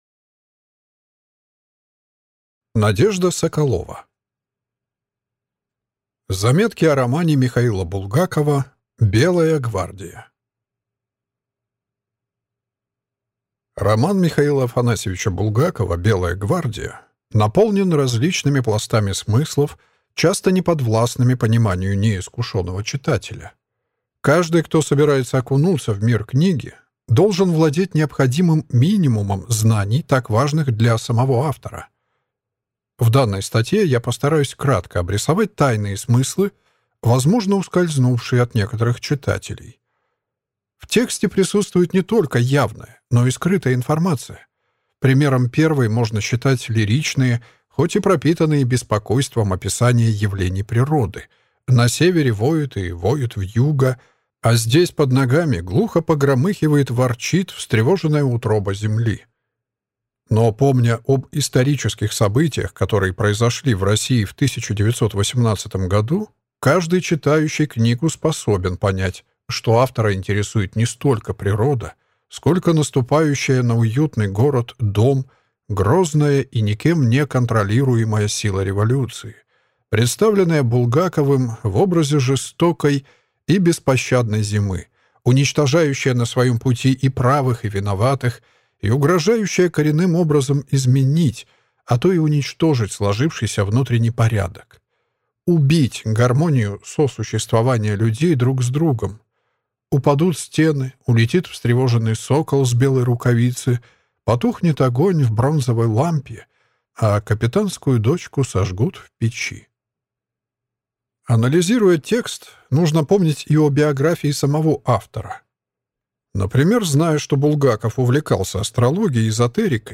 Аудиокнига Заметки о романе М. Булгакова «Белая гвардия» | Библиотека аудиокниг